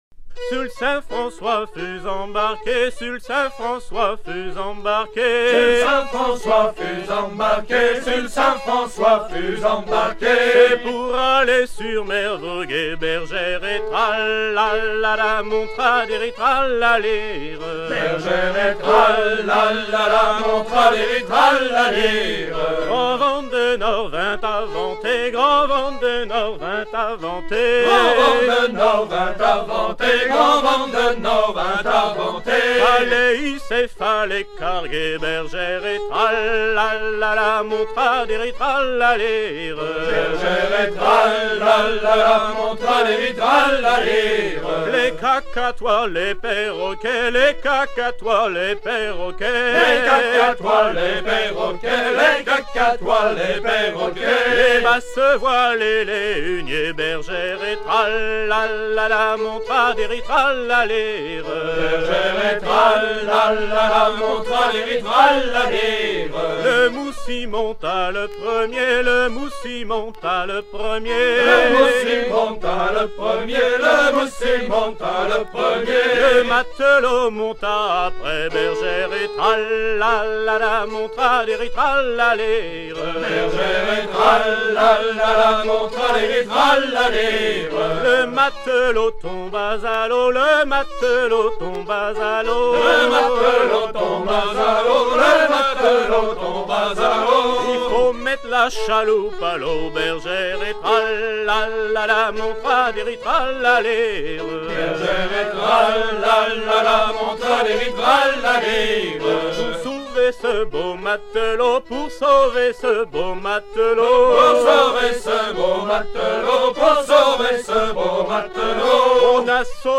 Version recueillie en 1973
à virer au cabestan
danse carrée